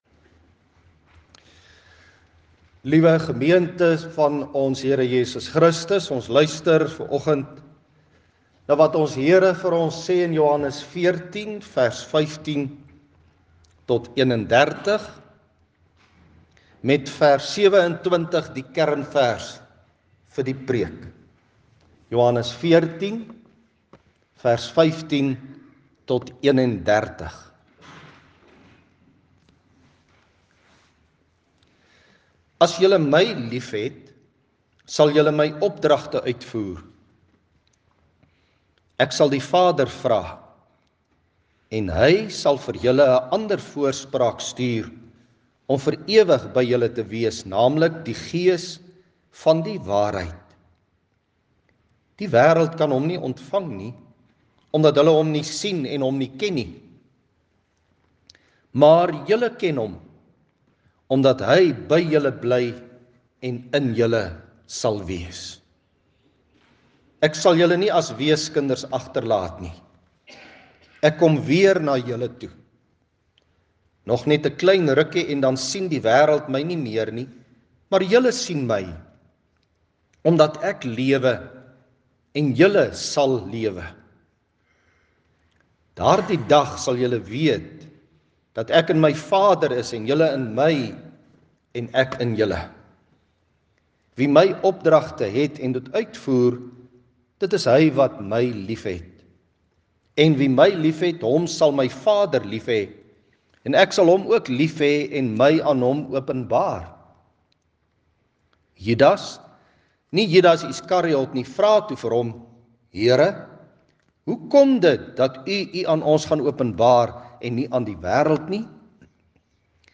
Die seën waarmee God ons in die erediens ontvang : Genade, barmhartigheid en vrede van Hom wat is en wat was en wat kom; en van die sewe Geeste voor sy troon; en van Jesus Christus die getroue Getuie, die Eerste wat uit die dood opgestaan het, en die Heerser oor die konings van die aarde.